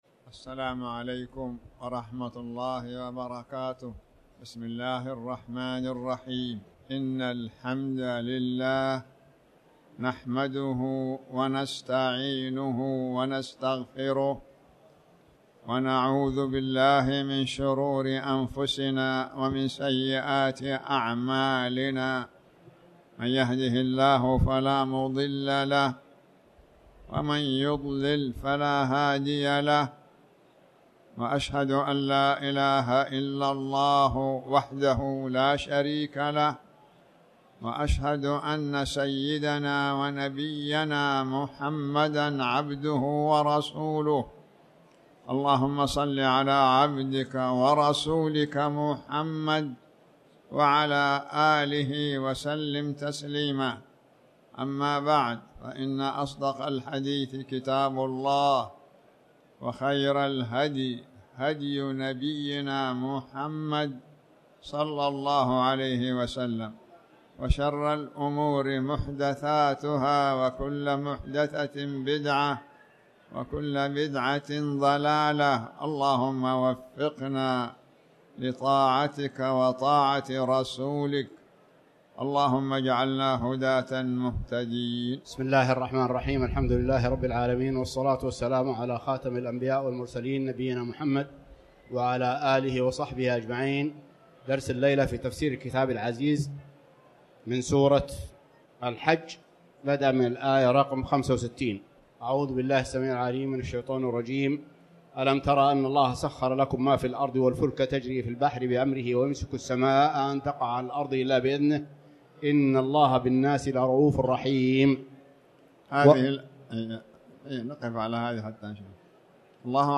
تاريخ النشر ٢٥ رجب ١٤٣٩ هـ المكان: المسجد الحرام الشيخ